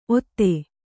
♪ otte